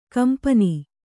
♪ kampani